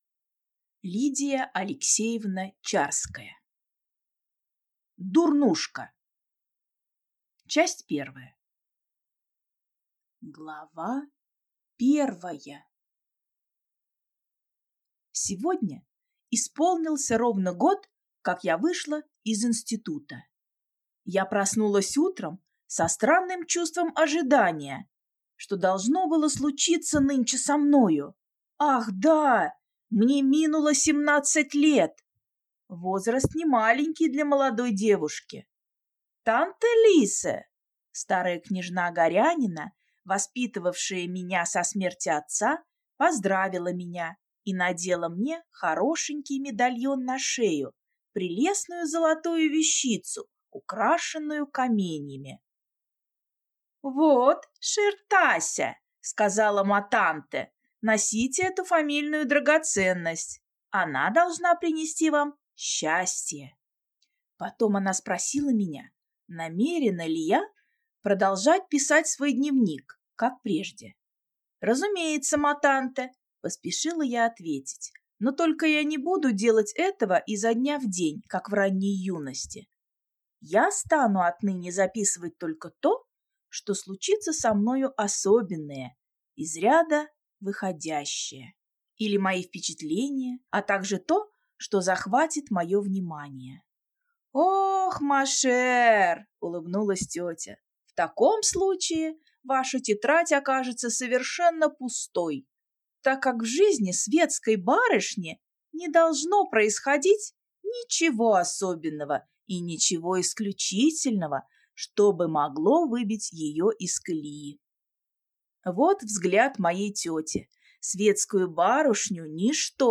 Аудиокнига Дурнушка | Библиотека аудиокниг